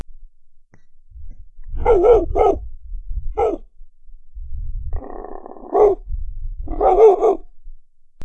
dog barking
Category 🐾 Animals
bark barking big bow dog woof wow sound effect free sound royalty free Animals